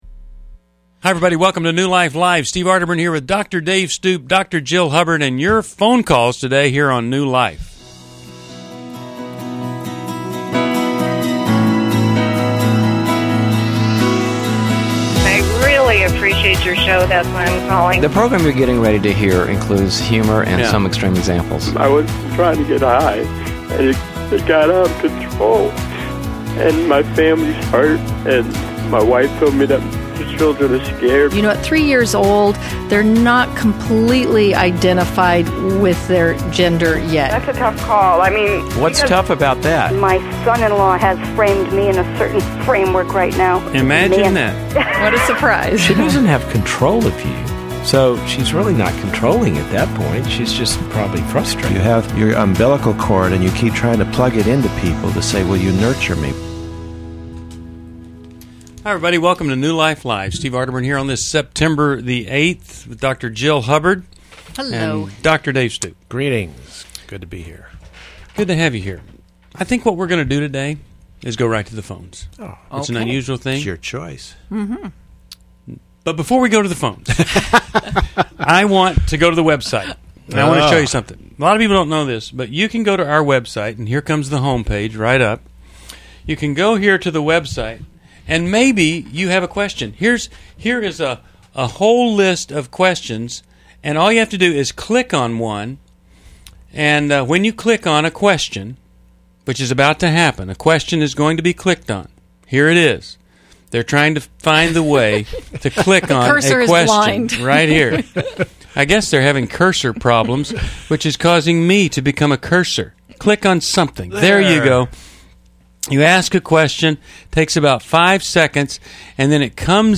Explore issues of pornography, finances, and parenting in relationships on New Life Live: September 8, 2011, with expert advice from our hosts.
Caller Questions: 1.